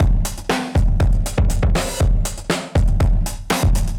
Index of /musicradar/dusty-funk-samples/Beats/120bpm/Alt Sound
DF_BeatB[dustier]_120-02.wav